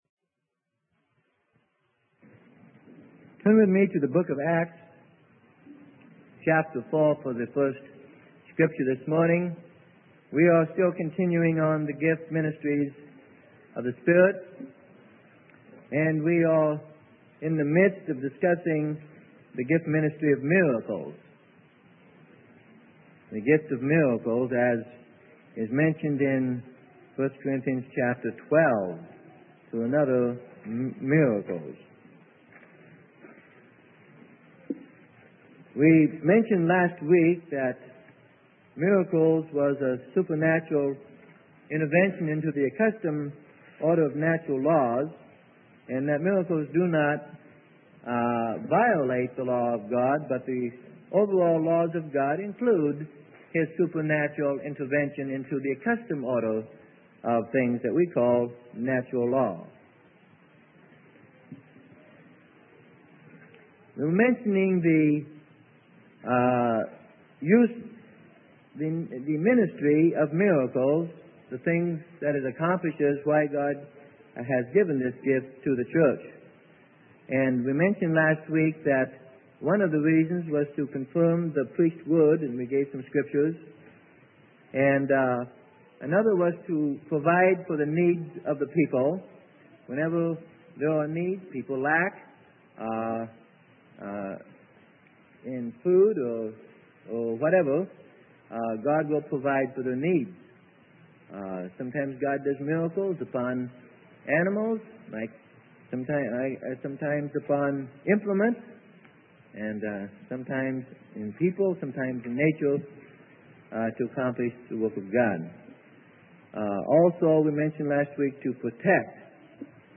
Sermon: Gifts of the Spirit - Part 8: Miracles Prophecy - Freely Given Online Library